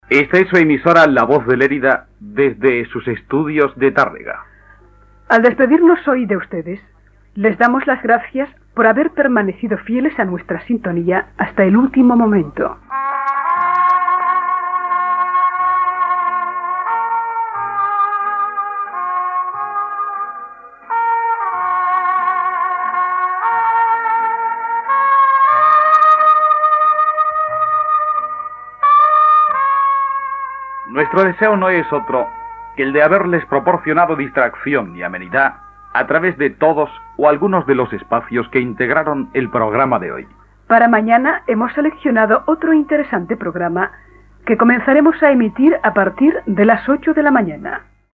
Comiat i tancament de l'emissió - Ràdio Tàrrega anys 60